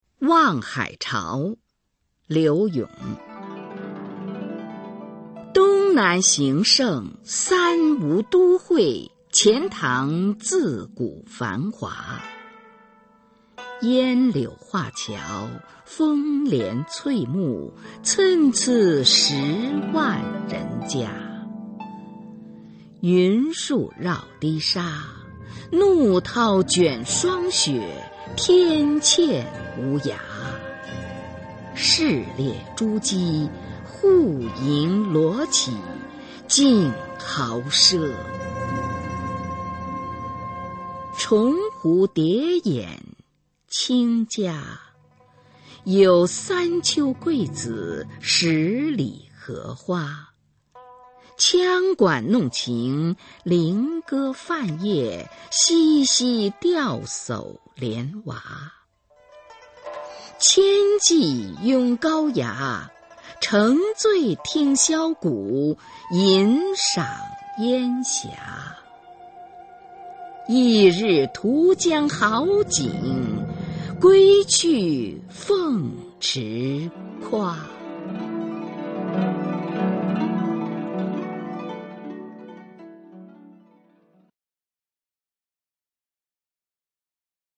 [宋代诗词朗诵]柳永-望海潮 古诗词诵读